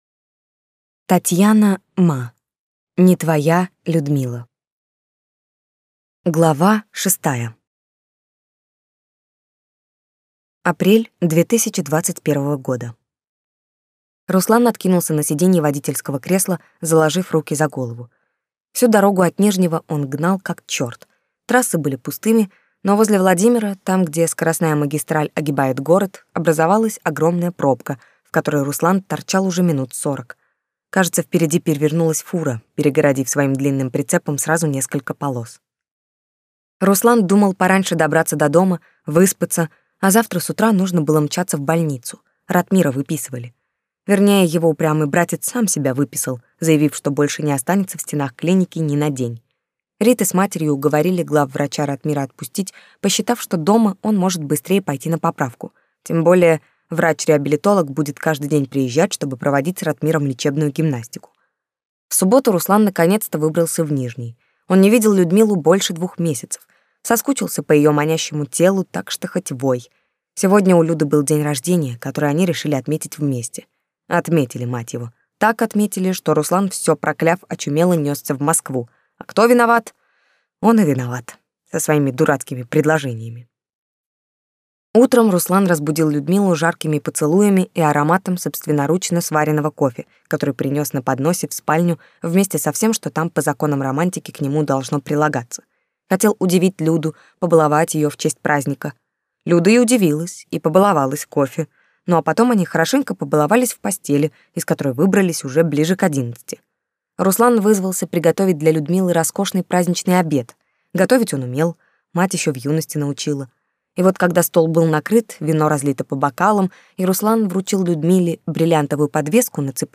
Аудиокнига Не твоя Людмила | Библиотека аудиокниг